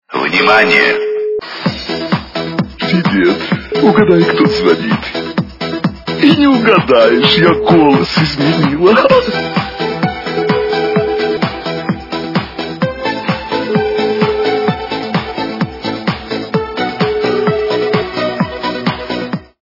При прослушивании Звонок от подруги - Привет, угадай кто звонит?... качество понижено и присутствуют гудки.